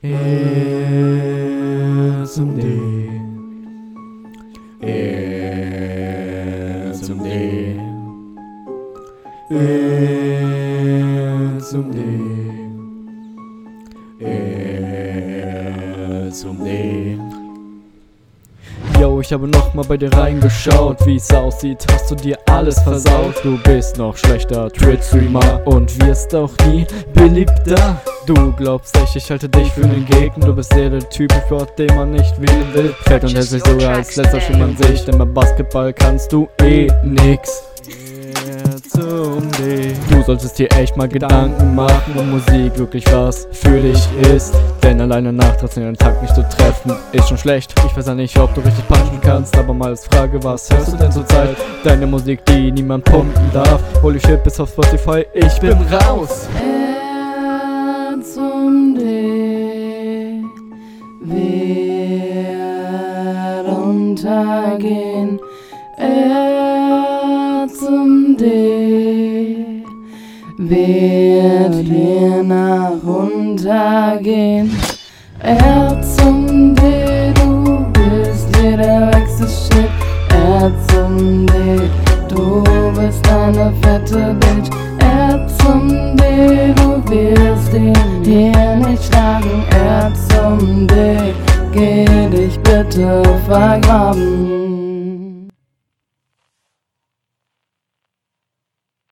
Egal, Verständlichkeit hier okay, du versuchst wenigstens was daraus zu machen.